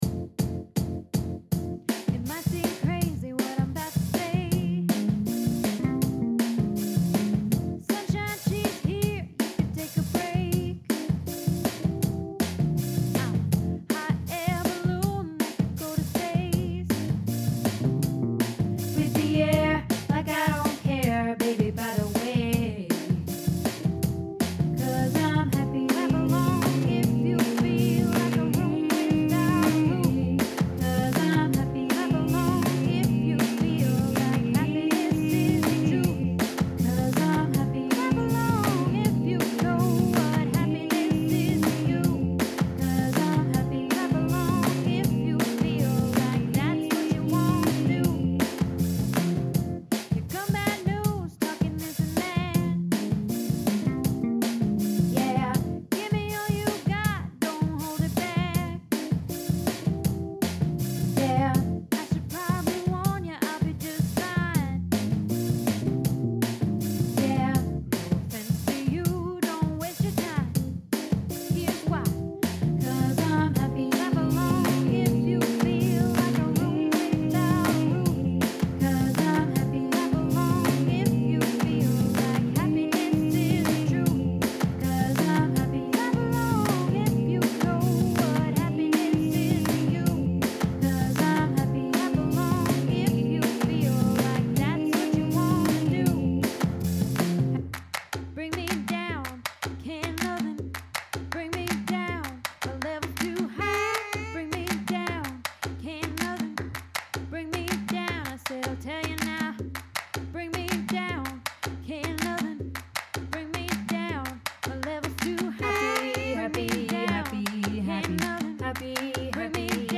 Happy Bass